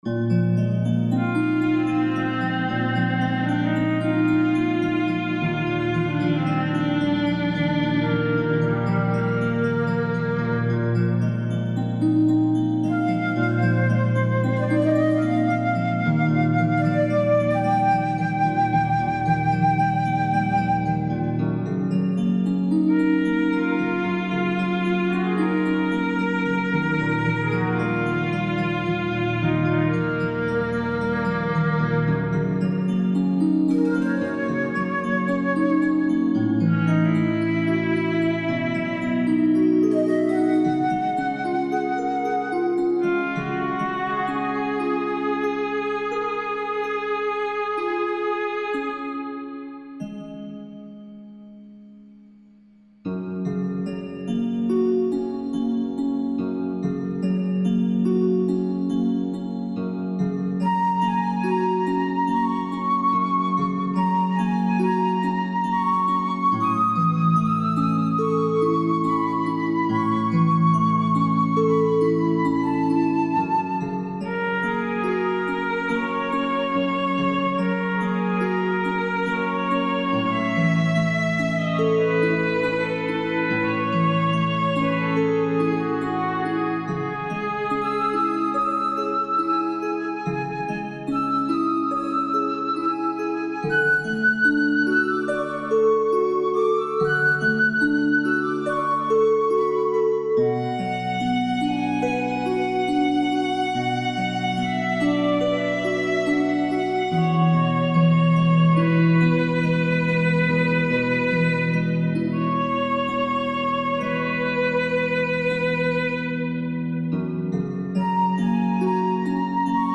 A harp accompanies a violin and flute in a love duet.
Tempo (BPM) 108/68